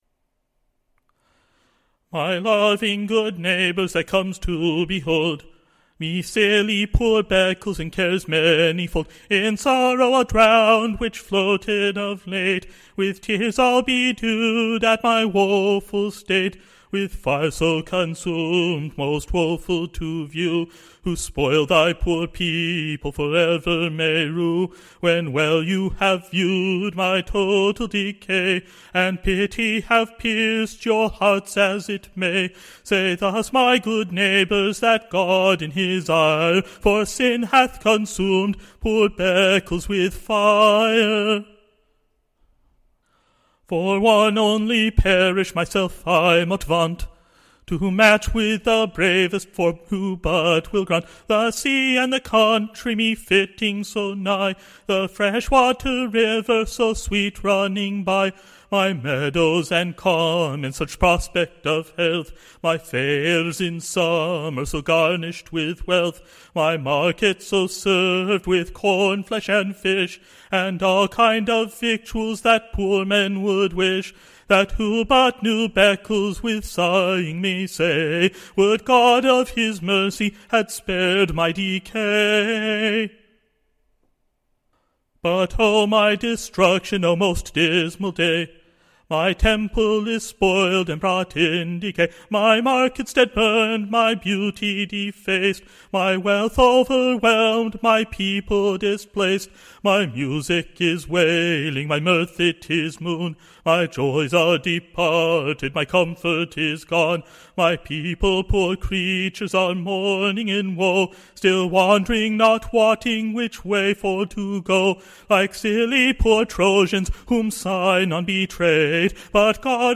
Ballad
Tune Imprint To the tune of Labandalashotte.